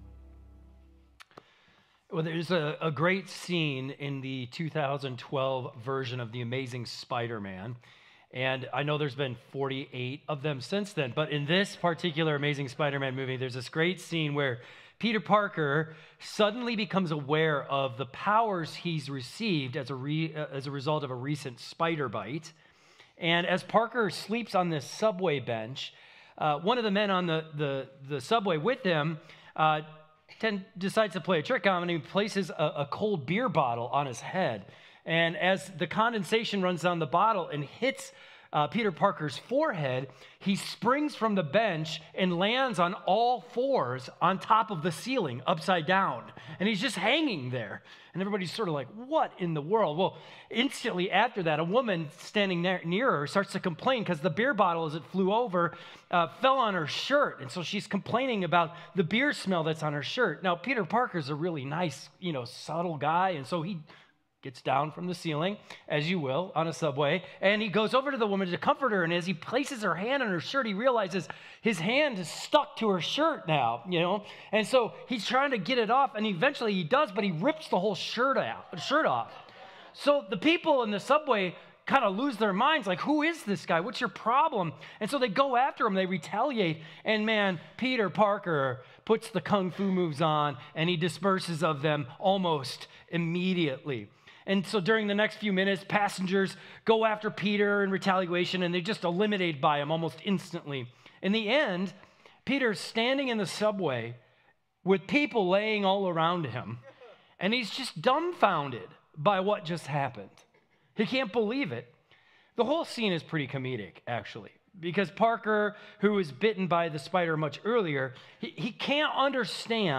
keyboard_arrow_left Sermons / Acts Series Download MP3